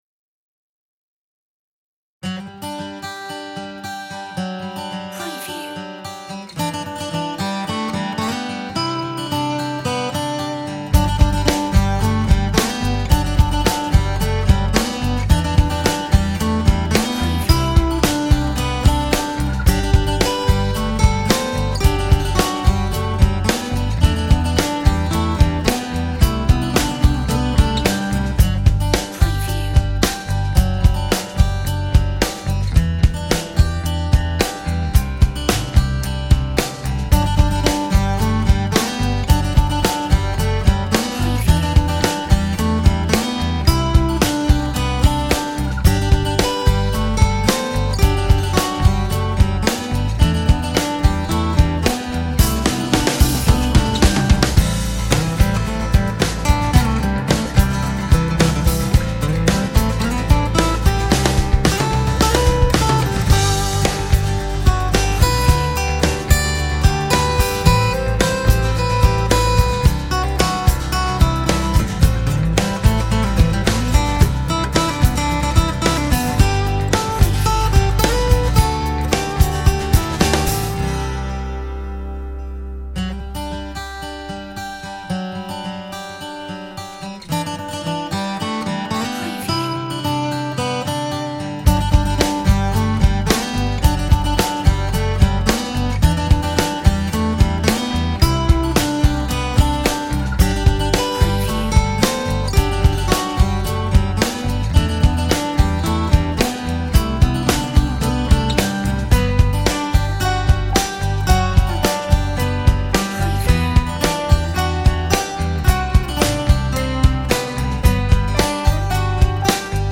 Acoustic Rock
Acoustic band sound